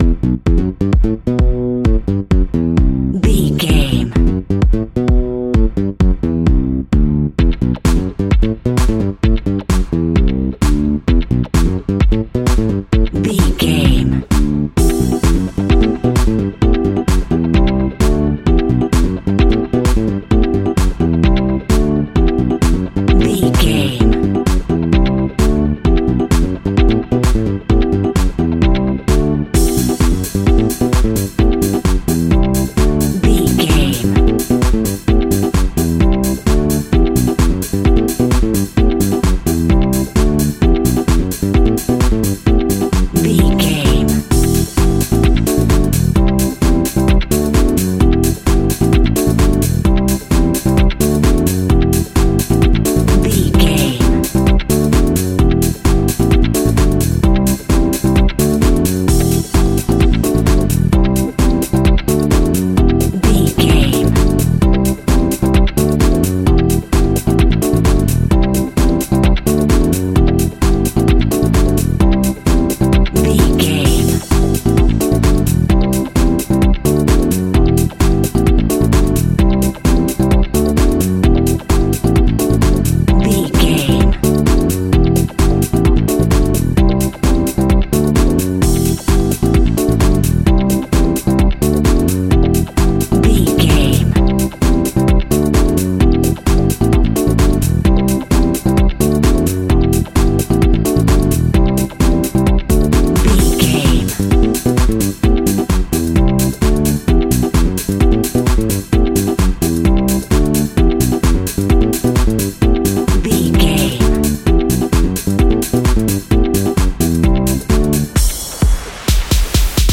Aeolian/Minor
F#
uplifting
energetic
funky
saxophone
bass guitar
drums
synthesiser
electric organ
funky house
nu disco
upbeat